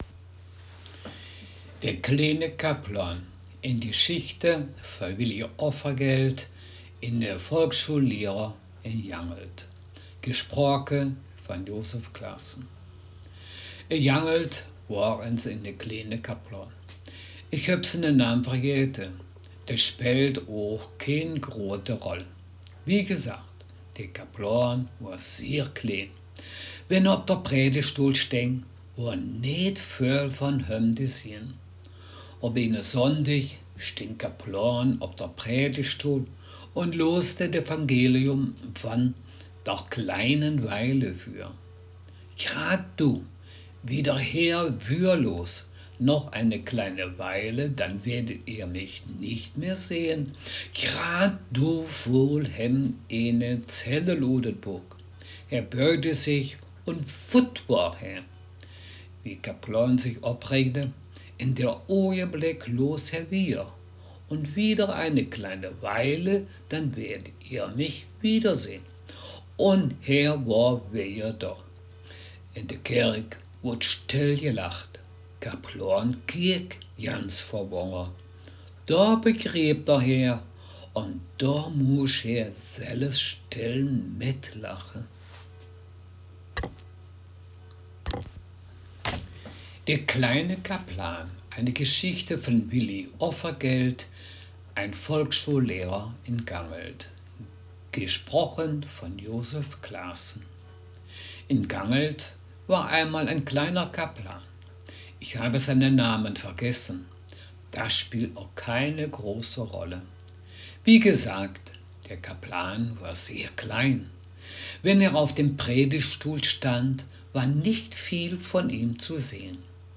Text Mundart
Gangelter-Waldfeuchter-Platt
Geschichte